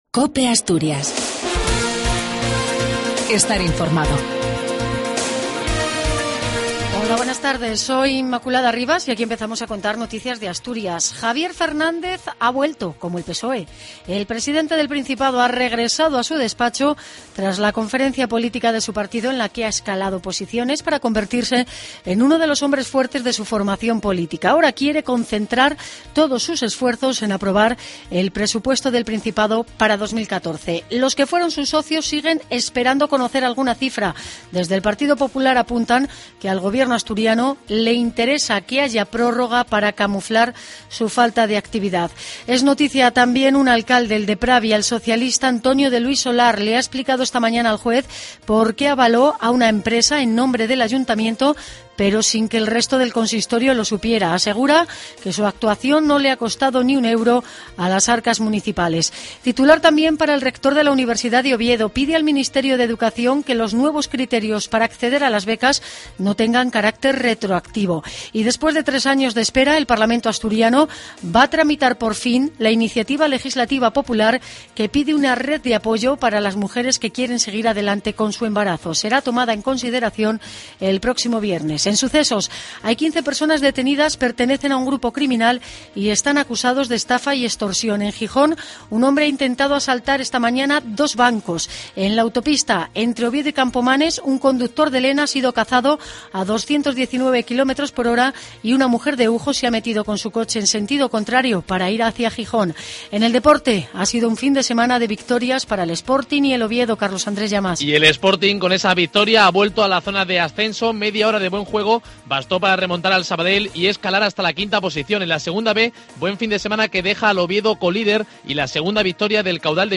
AUDIO: LAS NOTICIAS DE ASTURIAS Y OVIEDO AL MEDIODIA.